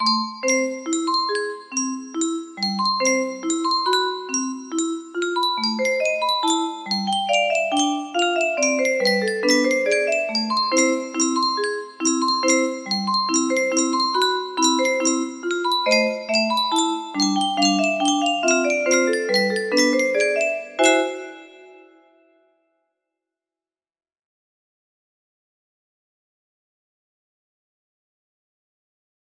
Tanz der Ahornblaetter music box melody